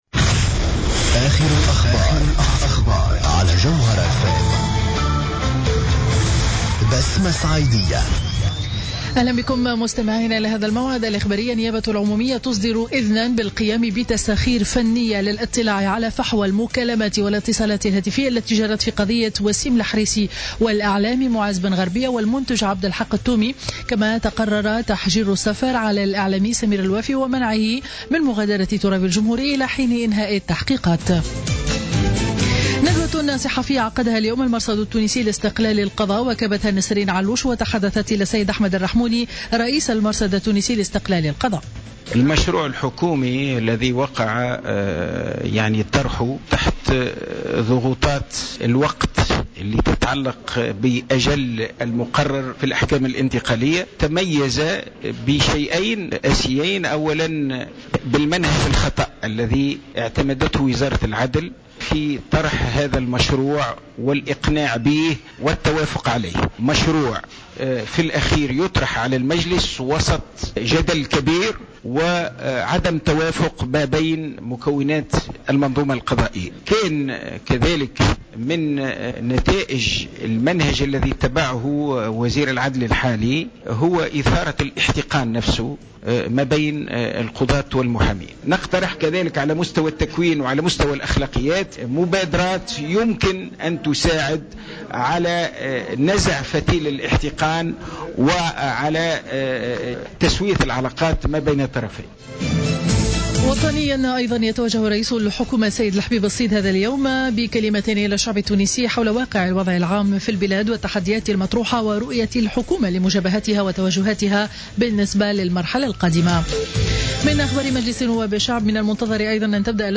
نشرة أخبار منتصف النهار ليوم الاثنين 16 مارس 2015